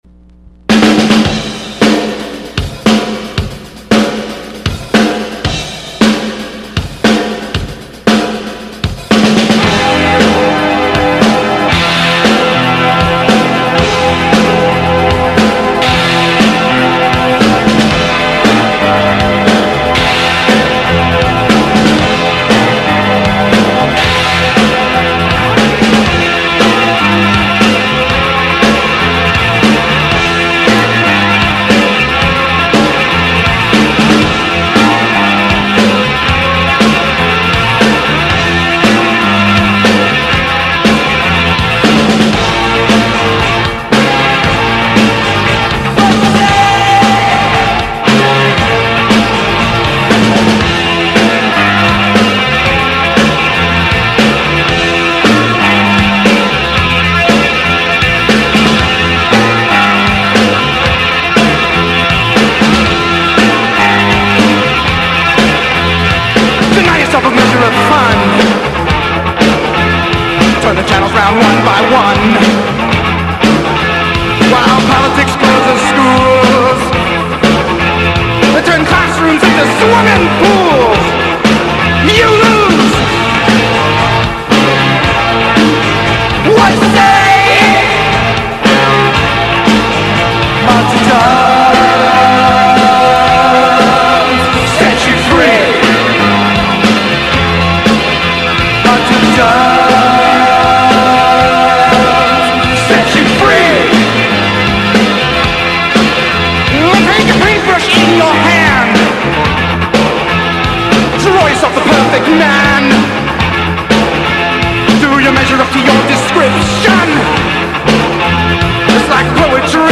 A Collection of 1980s and 1990s Rock tracks..